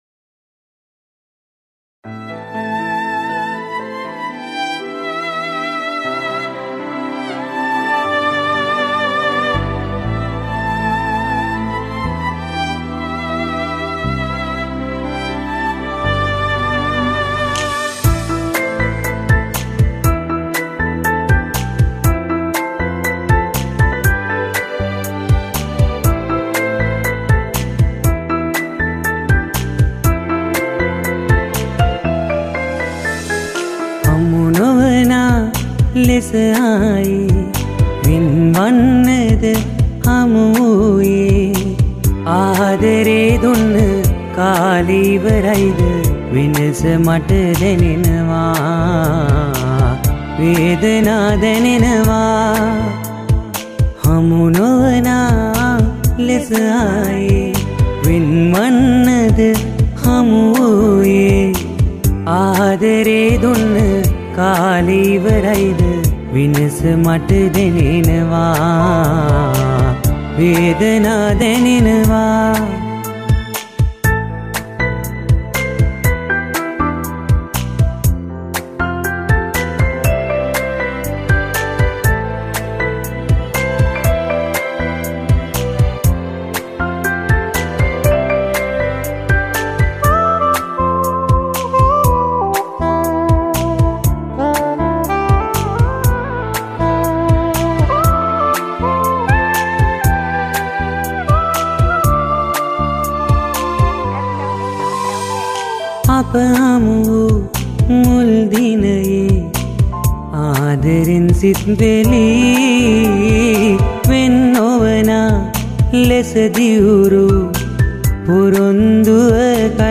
High quality Sri Lankan remix MP3 (4).
remix